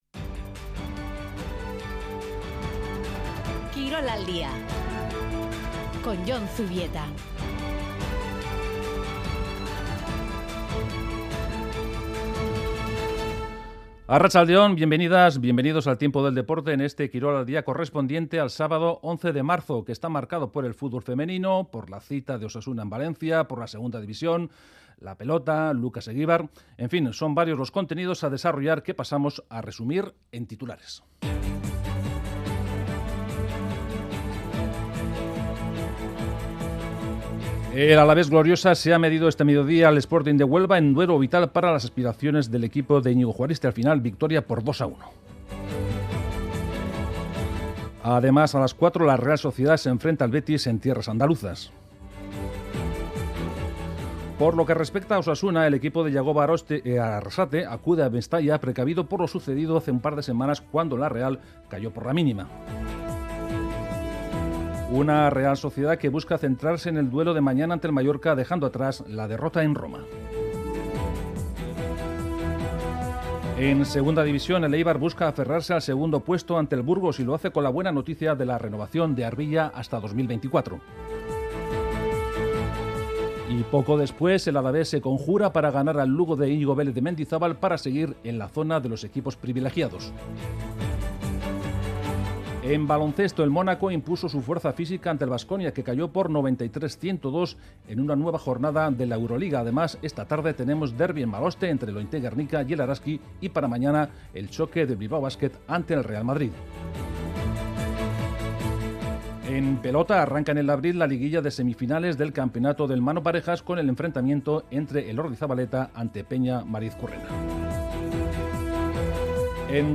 Informativo de actualidad deportiva